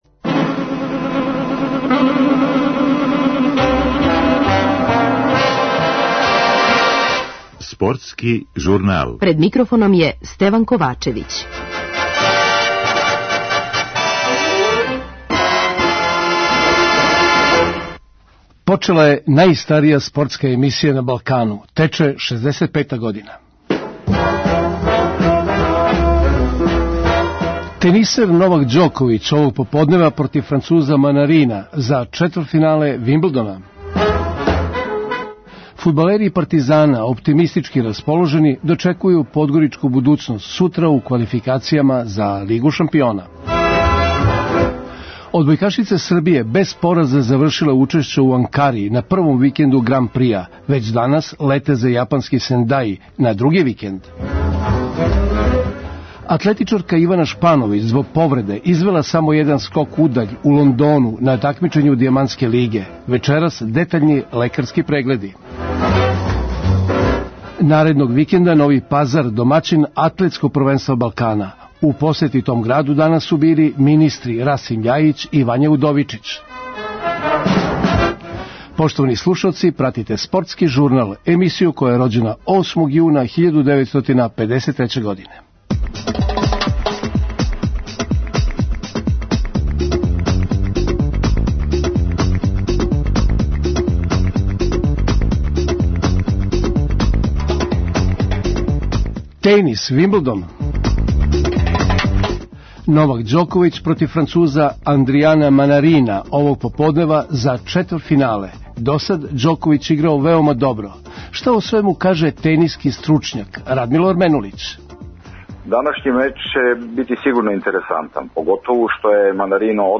преузми : 5.21 MB Спортски журнал Autor: Спортска редакција Радио Београда 1 Слушајте данас оно о чему ћете читати у сутрашњим новинама!